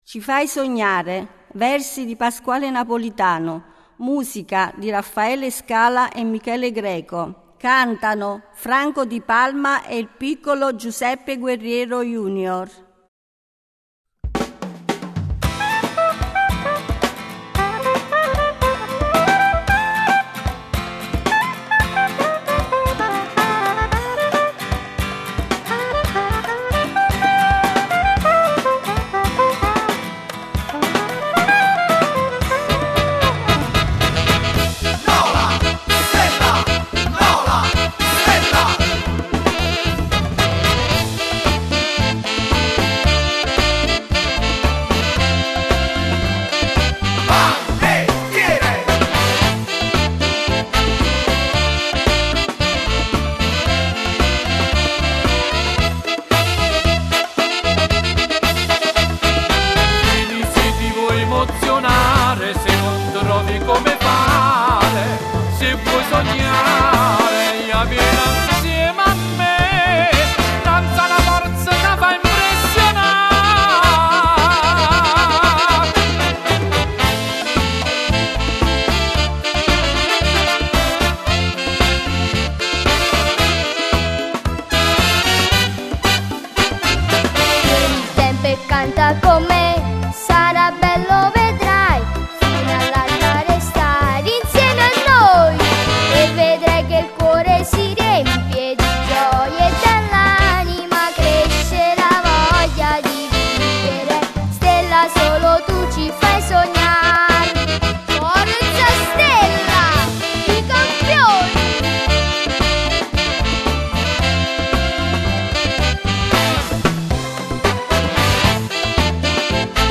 Giglio del Panettiere 2009